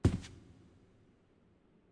FootstepHandlerFabric5.wav